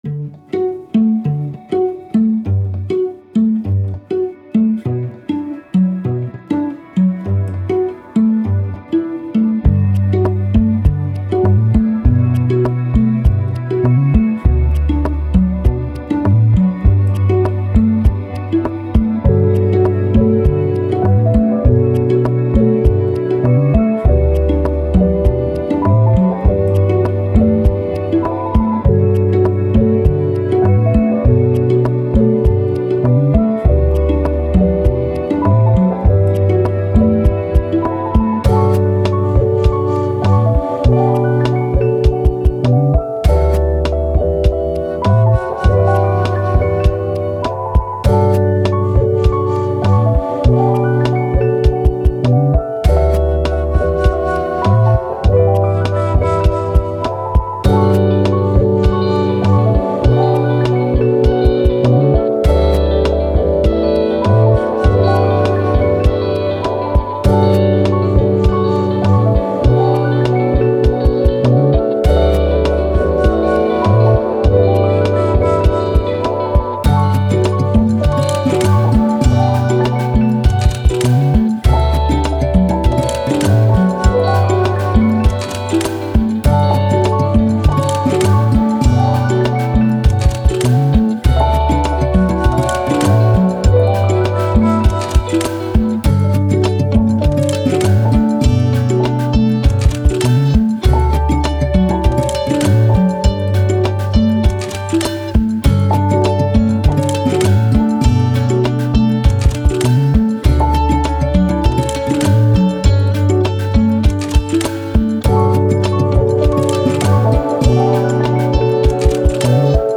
Ambient, Downtempo, Trip Hop, Chill